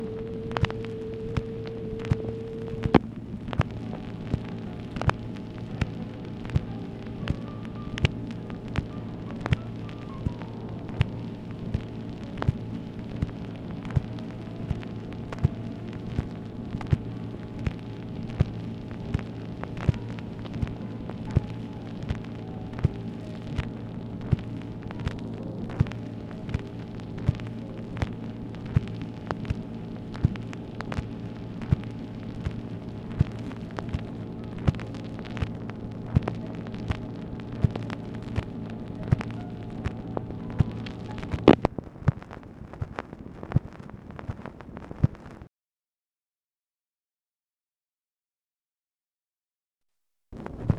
OFFICE NOISE, August 26, 1964